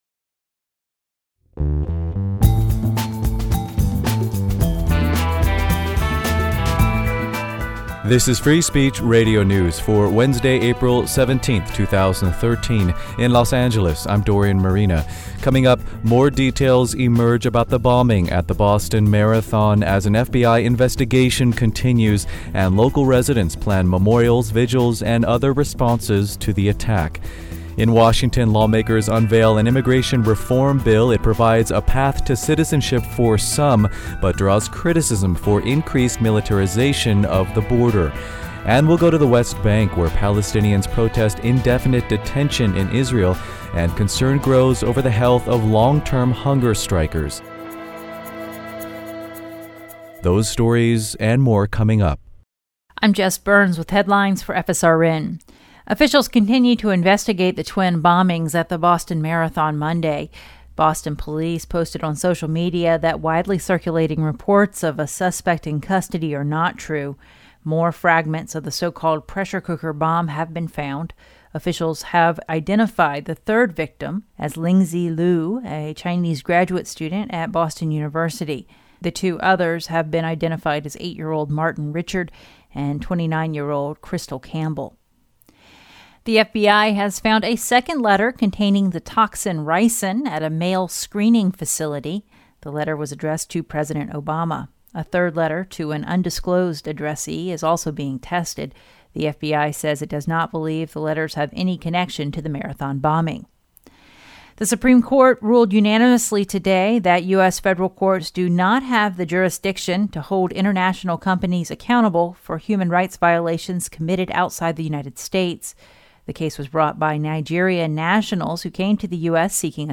Newscast for Wednesday, April 17, 2013